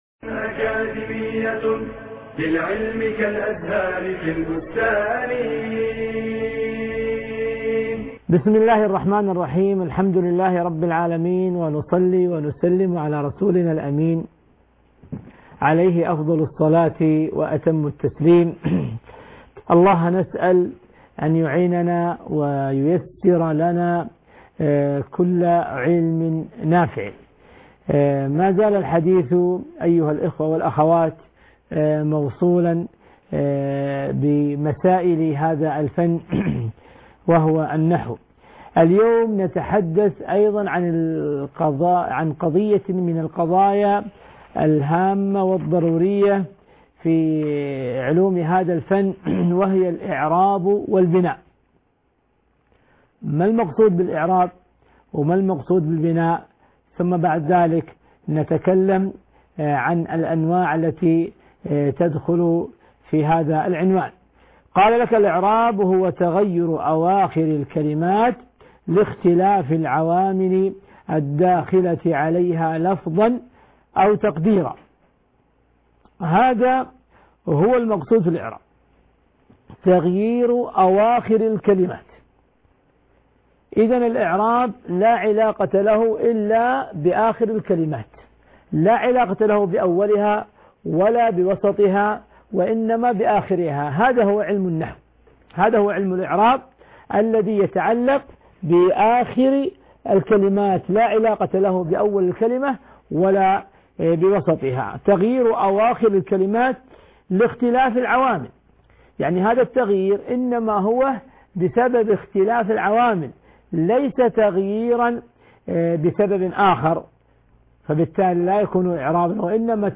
المحاضرة الثالثة عشر - الاعراب والبناء